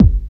18 KICK 2.wav